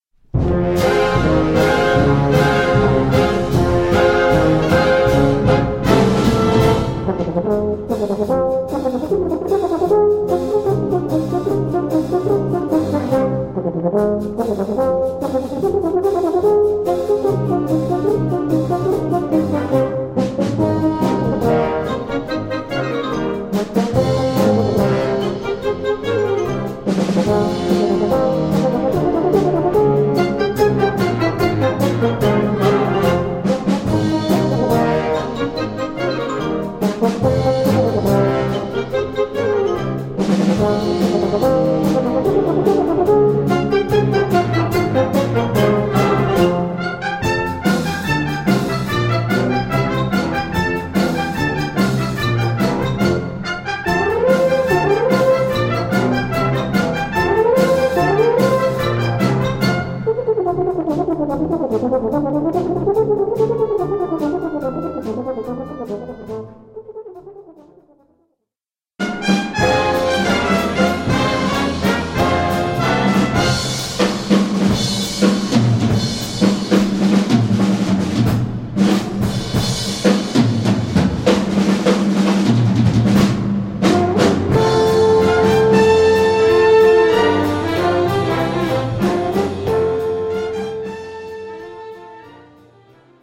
Gattung: für Soloinstrument in B und Blasorchester
Besetzung: Blasorchester
Im modernen Sound gehalten.